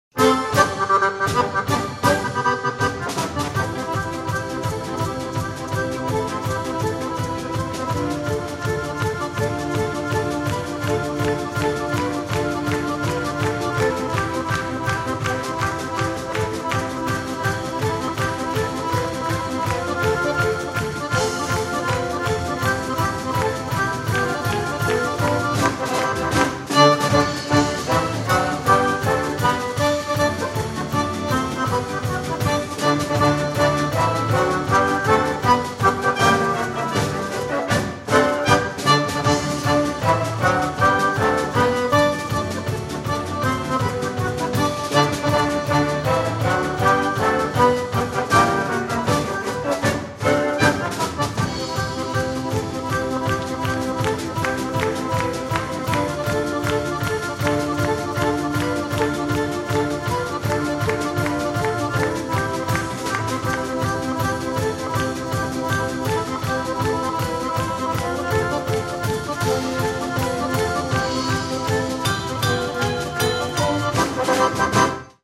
Konzert 2005 -Download-Bereich
-------Die Big Band-------